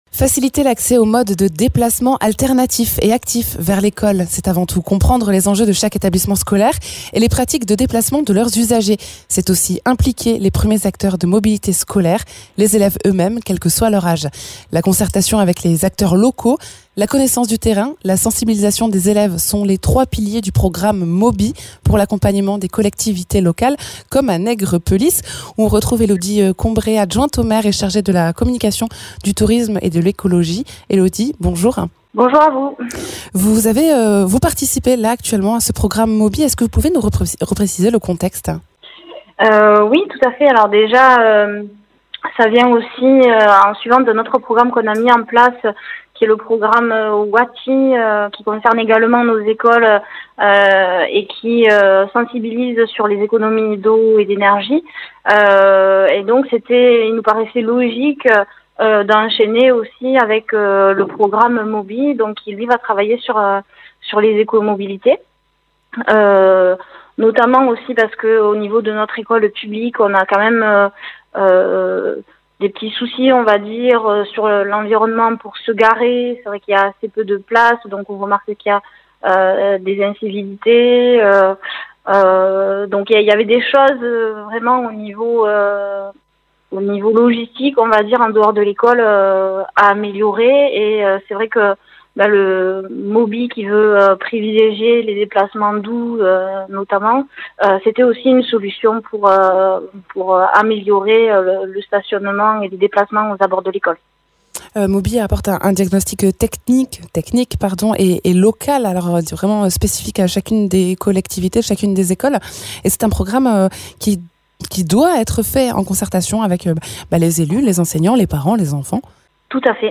Interviews
Invité(s) : Elodie Combret, adjointe au maire de Nègrepelisse et chargée de la communication, tourisme et écologie